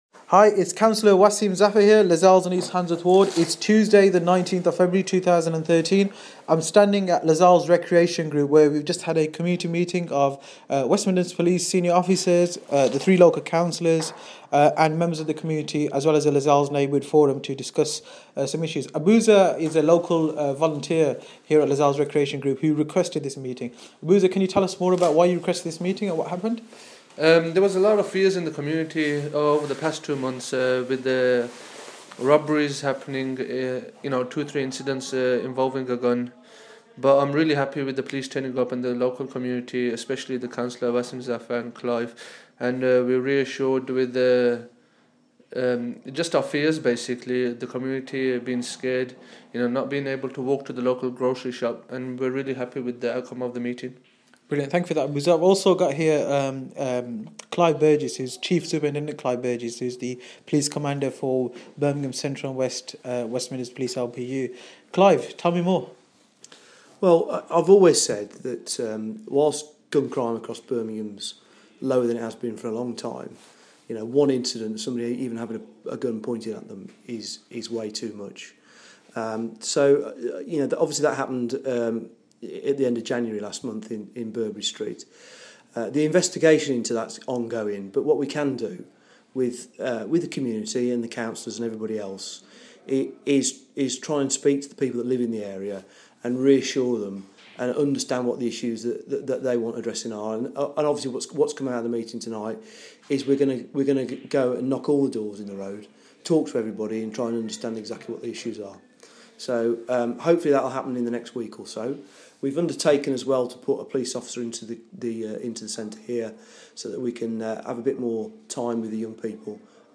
A meeting of community activists, local Councillors and West Midlands Police took place at LRG to discuss reassurance of the local community following some recent incidents.